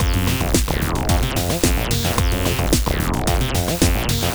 UR 303 acid bass 1 (w beat).wav